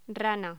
Locución: Rana
Sonidos: Voz humana